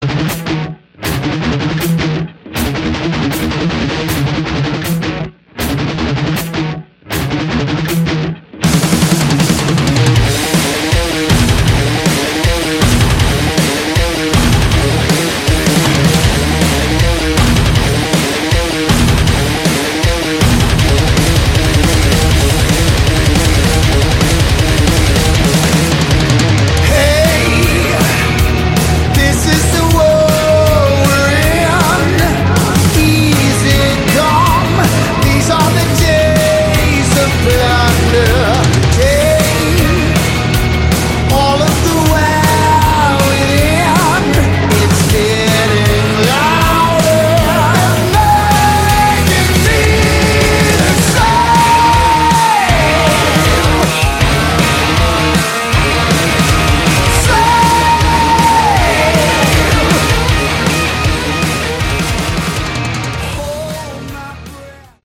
Category: Hard Rock
guitars
bass
vocals
drums